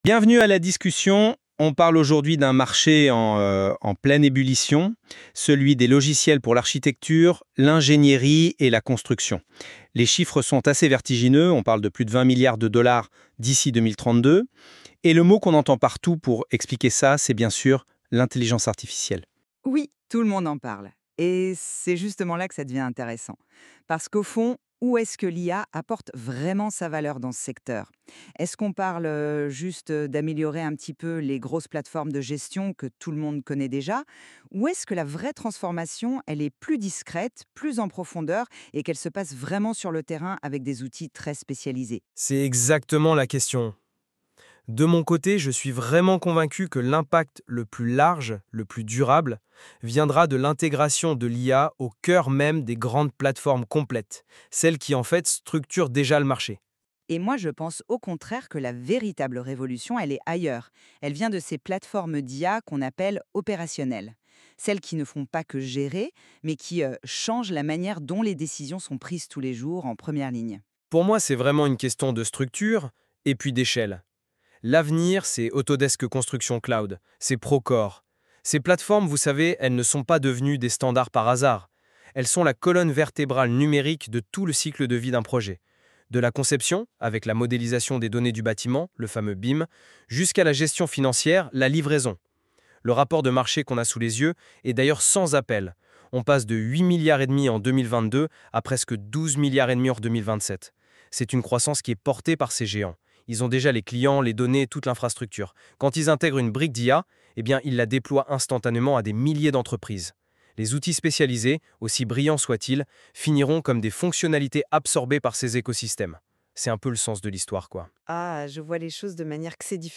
[!Success]Ecoutez le débat contradictoire /uploads/default/original/2X/a/a8ff3fb022b108bf3535d9c5f3908fb7fd0b27dd.mp3